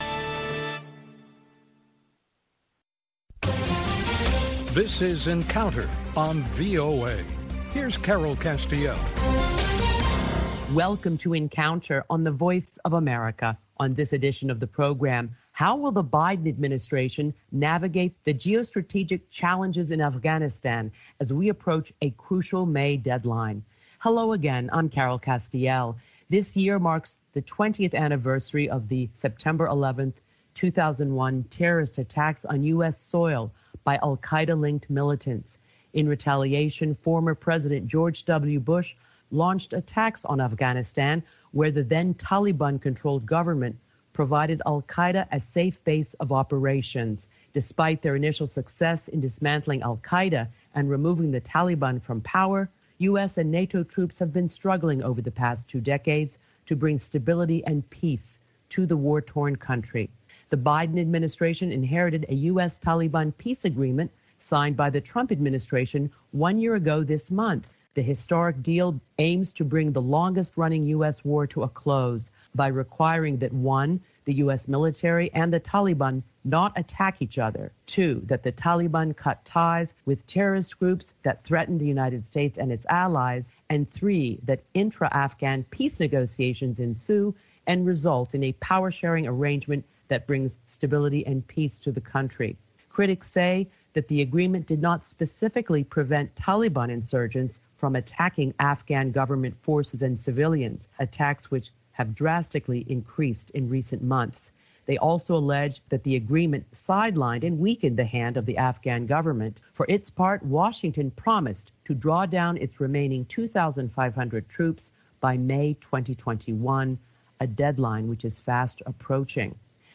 talks with panelists